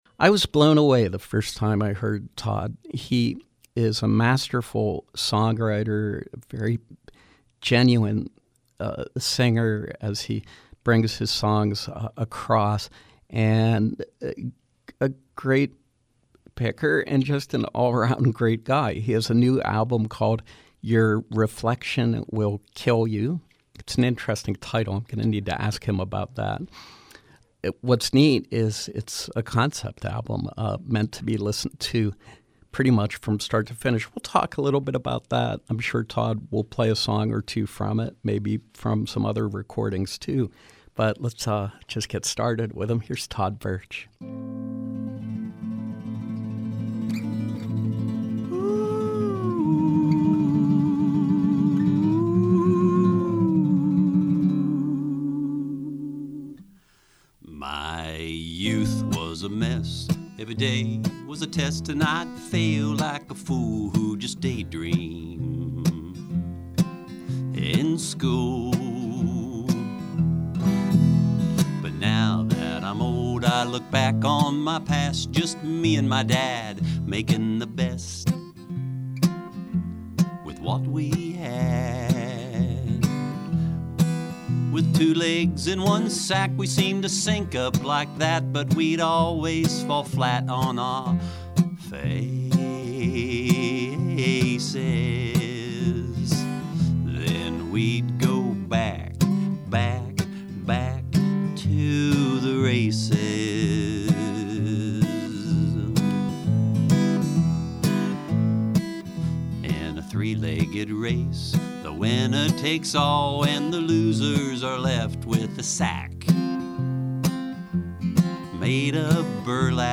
Live performance with singer/songwriter